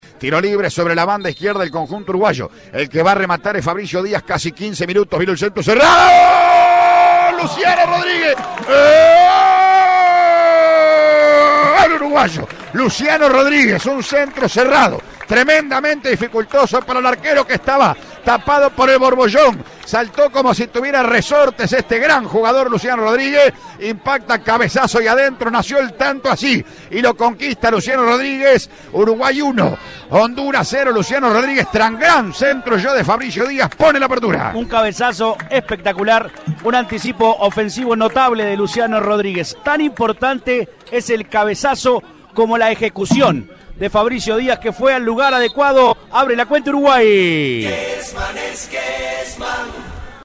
ESCUCHÁ EL RELATO DE GOL